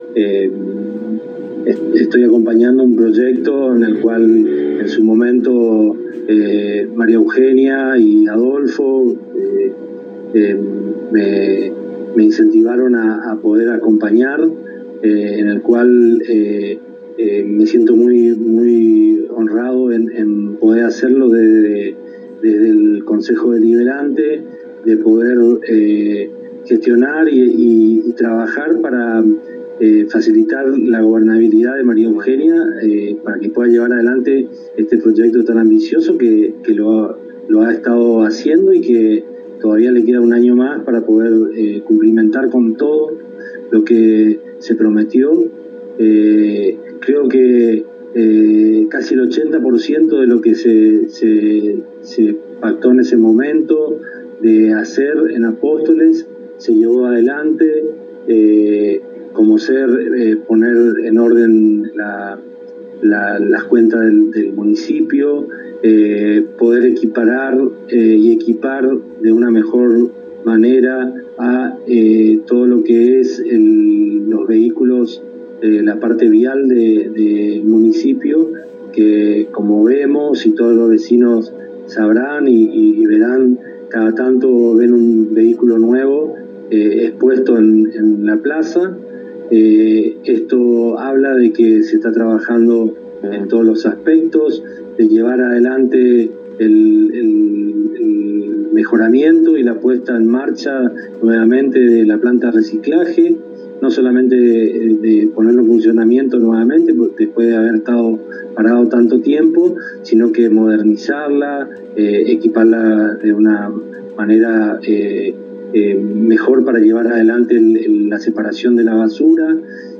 Alberto Tito Poliszuk Presidente del H.C.D. de Apóstoles en un extenso diálogo con la ANG expresó que en el 2019 cuando la actual gestión asumió la responsabilidad de conducir Apóstoles, primero tuvo que poner las cuentas municipales en orden, luego poner en condiciones el Parque Vial para poder dar cumplimiento a los compromisos asumidos con el pueblo en la campaña electoral, compromisos que de acuerdo a la visión del parlamentario se ha cumplido en un ochenta por ciento.